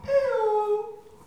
ajout des sons enregistrés à l'afk ...